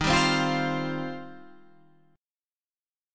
D#6add9 chord